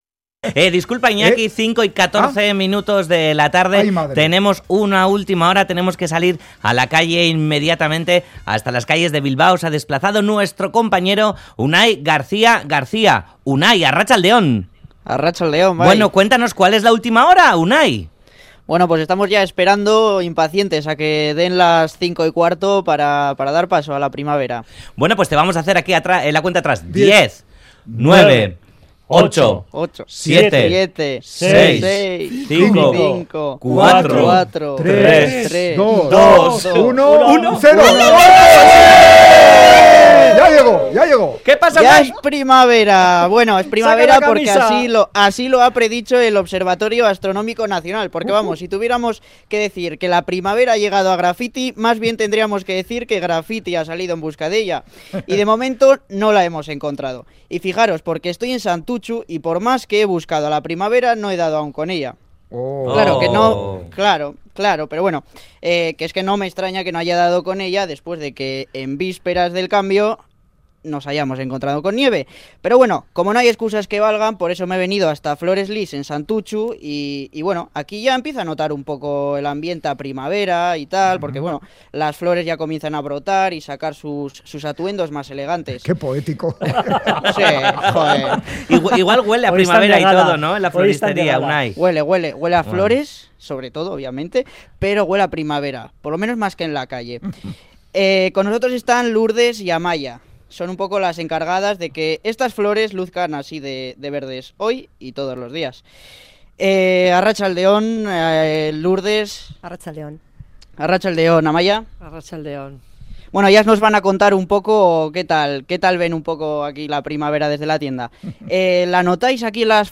Audio: Graffiti sale a la calle en busca de la primavera, donde la hemos encontrado en Flores Lys, quienes nos han recibido de brazos abiertos a la vez que a la primavera.